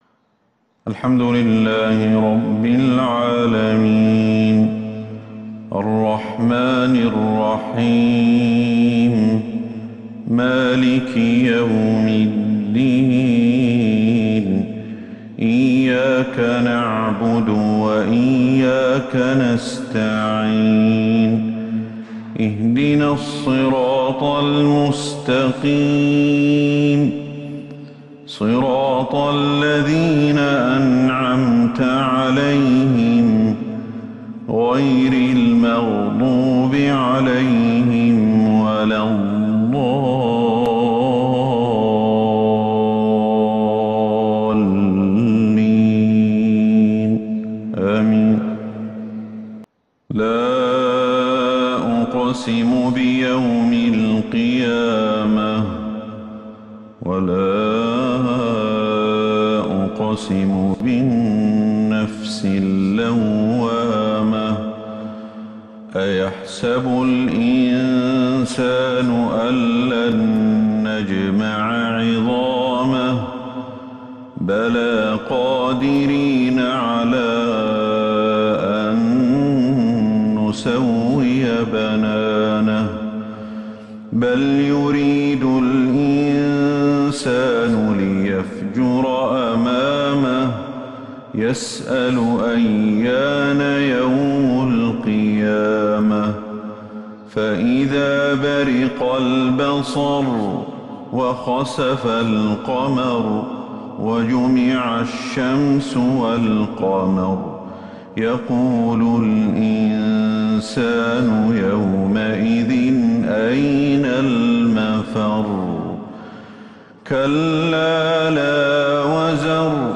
فجر الأحد 19 صفر 1443 هـ سورة {القيامة} كاملة > 1443 هـ > الفروض - تلاوات الشيخ أحمد الحذيفي